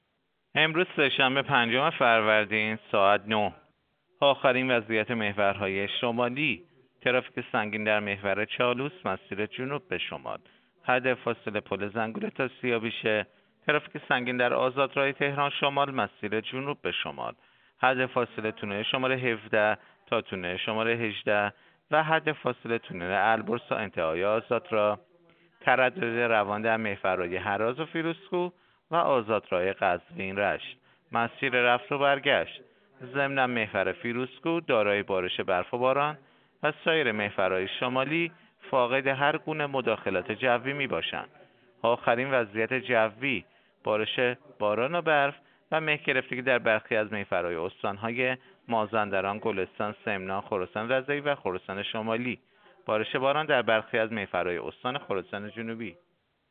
گزارش رادیو اینترنتی از آخرین وضعیت ترافیکی جاده‌ها ساعت ۹ پنجم فروردین؛